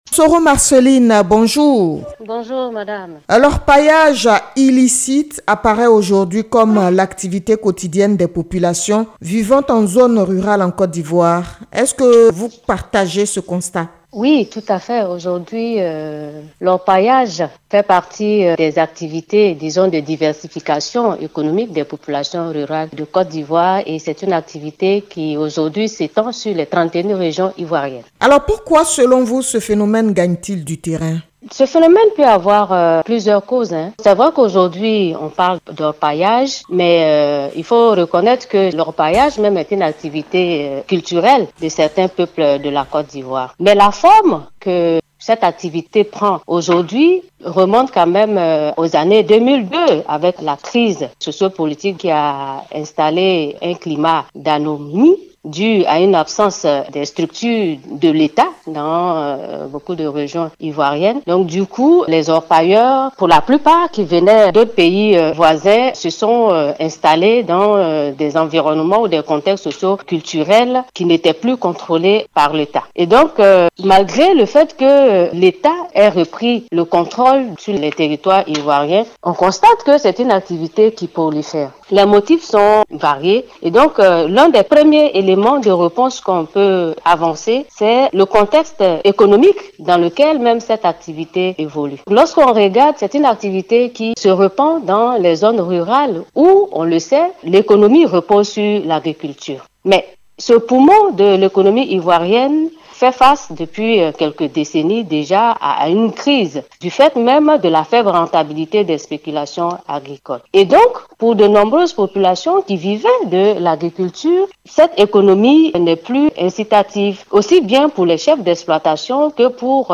Un entretien à suivre immédiatement .